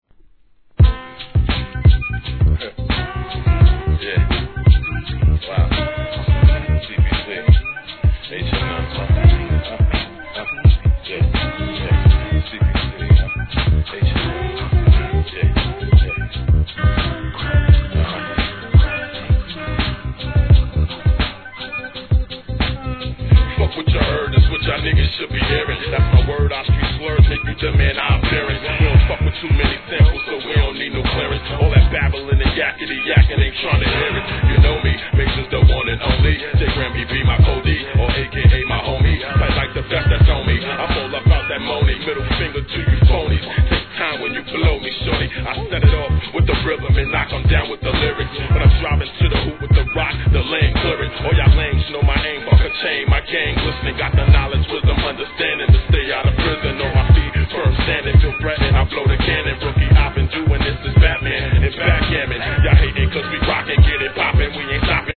G-RAP/WEST COAST/SOUTH
疾走するBEATに南部特有のチキチキ感がたまらないインディー物!